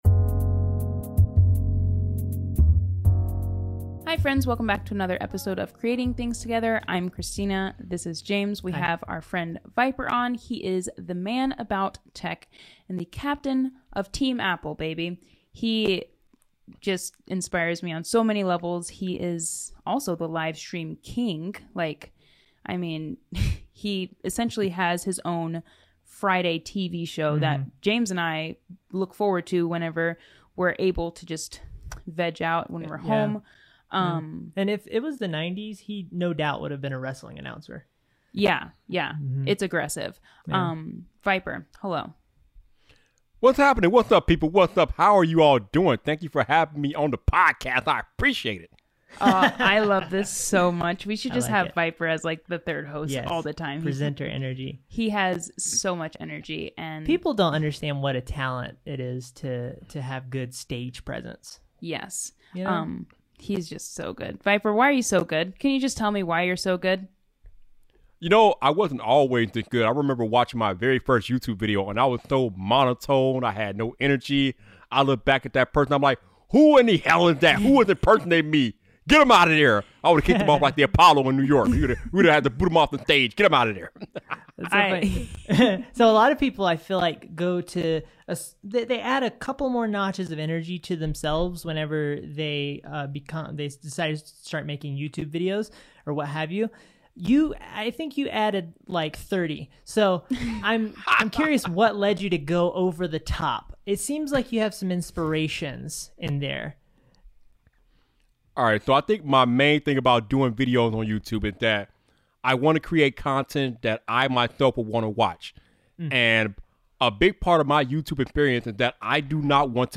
*Apologies for the crackle on our end of the audio on this episode.